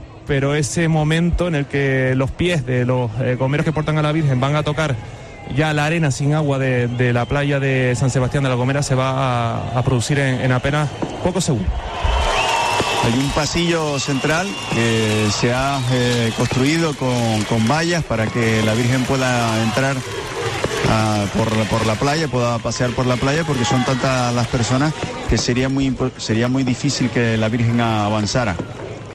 Así contamos en COPE Canarias la llegada de la Virgen de Guadalupe a la playa de San Sebastián